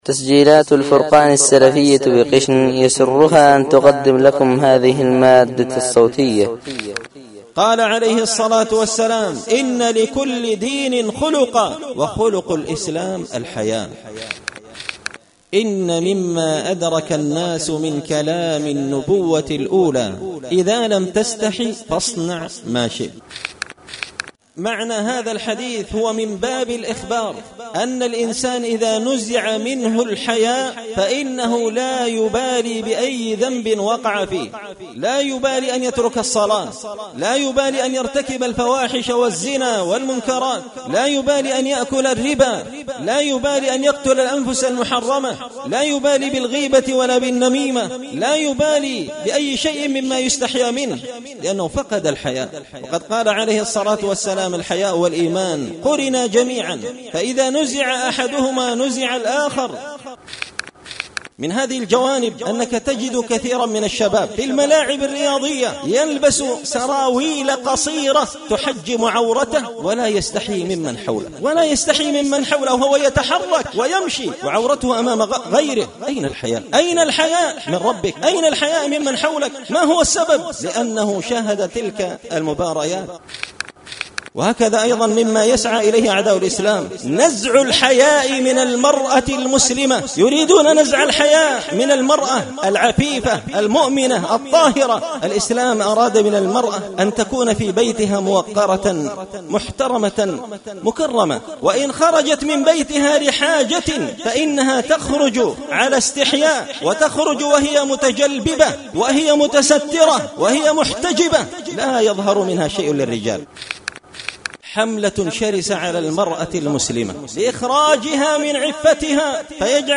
خطبة جمعة بعنوان – لكل دين خلقا وخلق الإسلام الحياء
دار الحديث بمسجد الفرقان ـ قشن ـ المهرة ـ اليمن